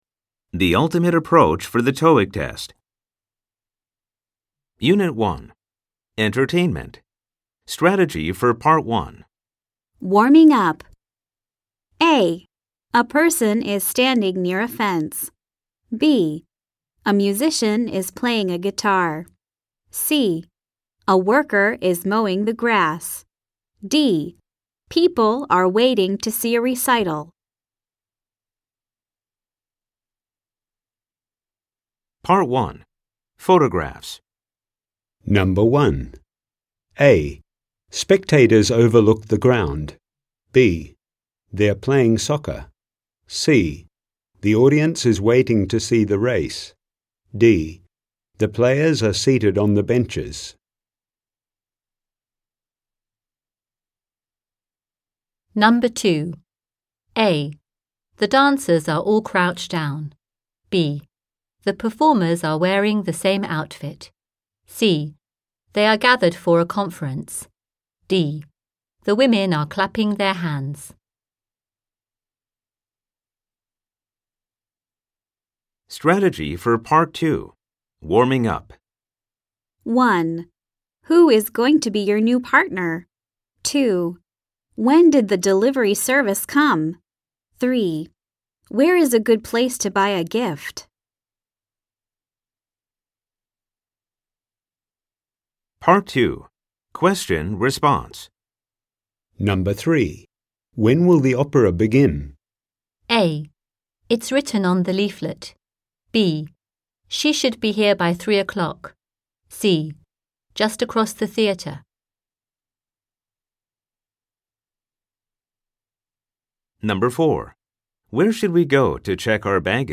吹き込み Amer E ／ Brit E ／ 他